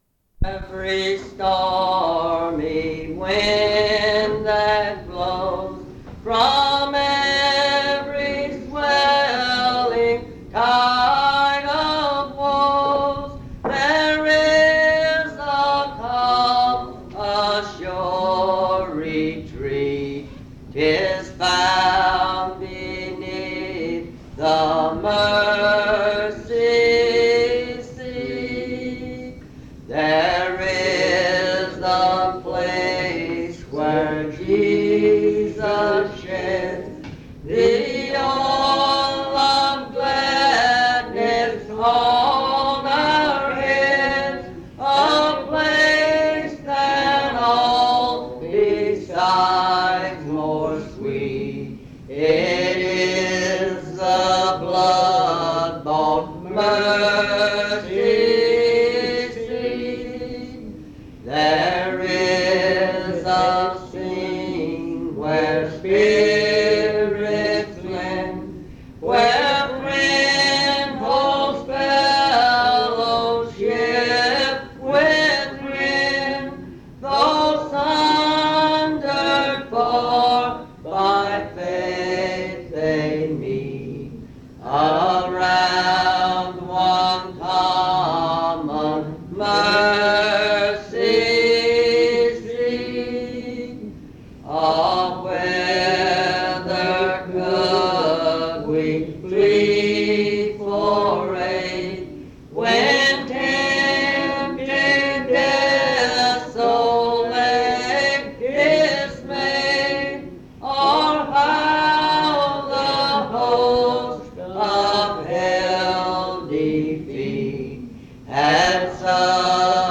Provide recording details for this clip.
at Monticello Primitive Baptist Church